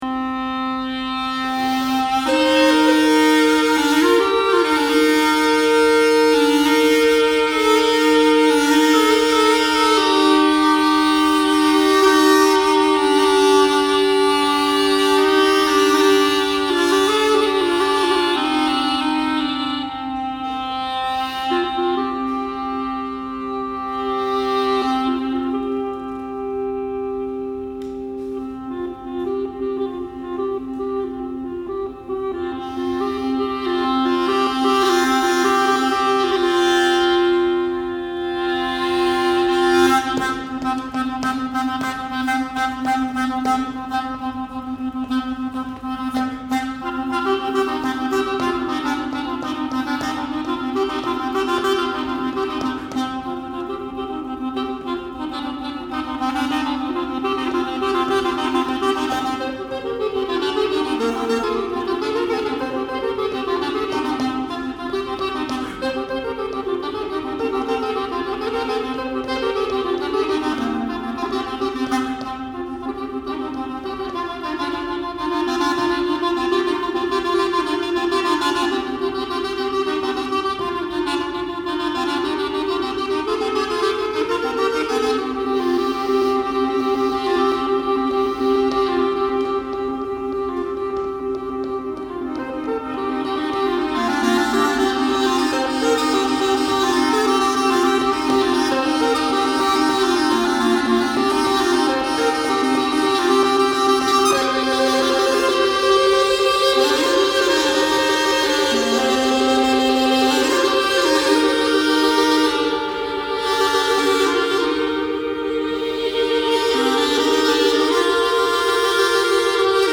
Click here to hear the sound of a detailed replica of the Louvre aulos